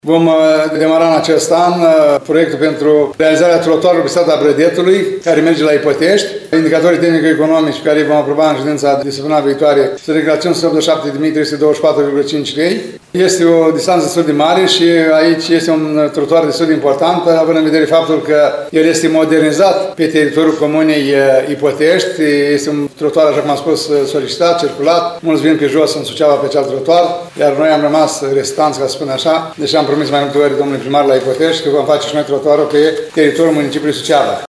Primarul ION LUNGU a declarat astăzi că – după finalizarea lucrărilor din zona Bebelușul – va fi realizat și trotuarul spre Ipotești.